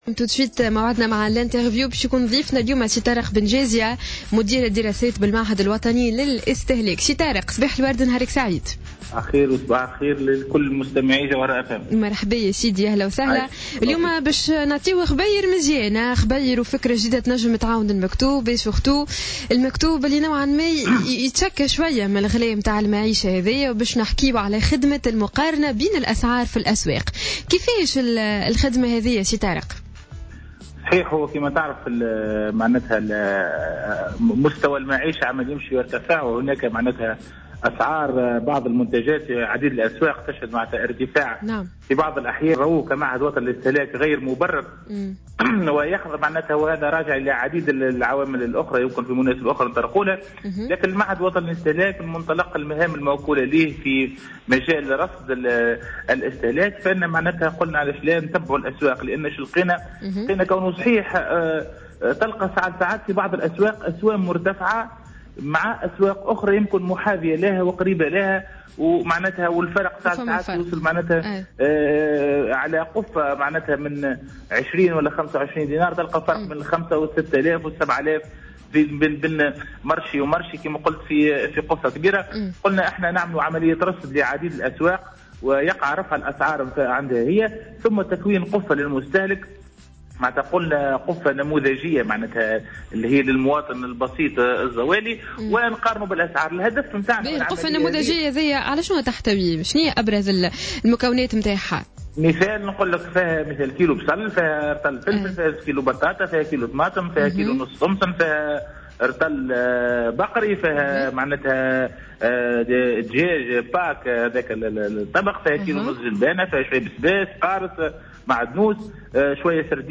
في مداخلة له على جوهرة "اف ام"